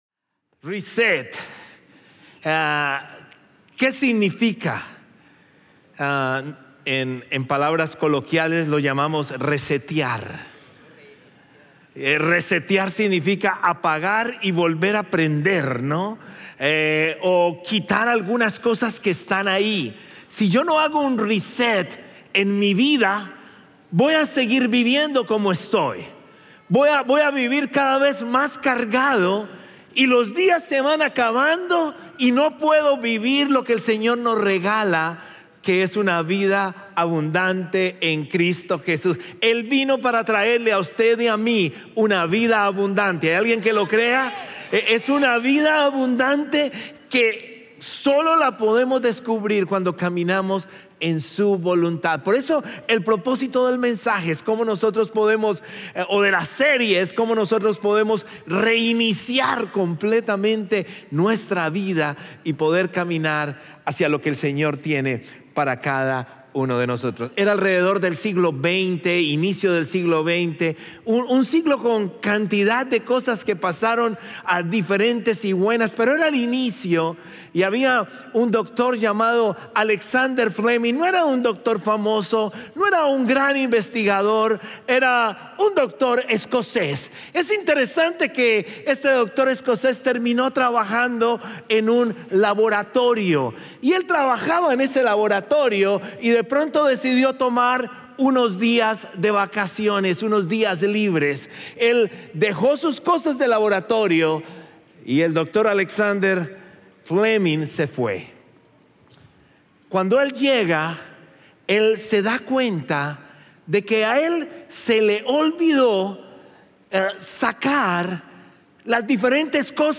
Sermones North Klein – Media Player